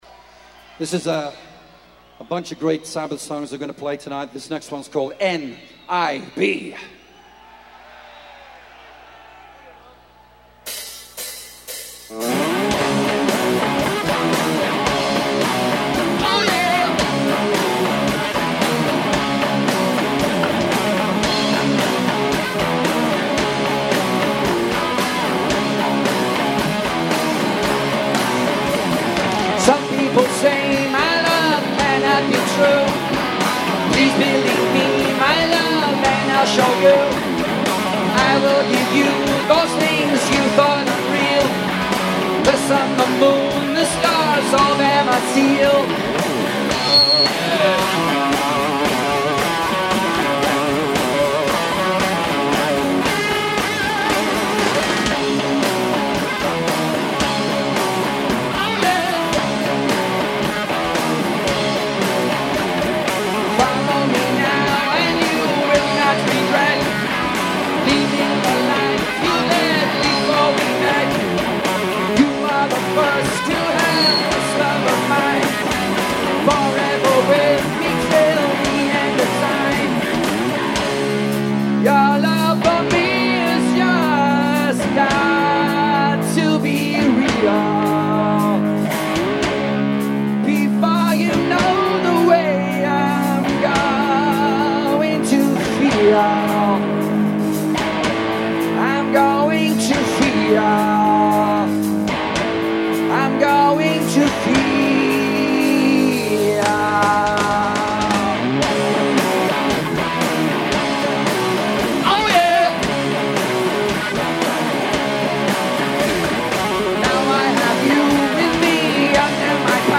Sources: Soundboard